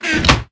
chestclosed.ogg